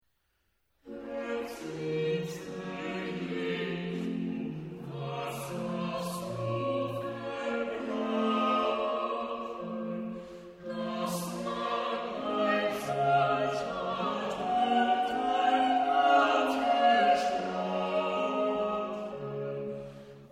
Bach_Choral_Herzliebster_Jesu_Zeilen_1_2_nw.mp3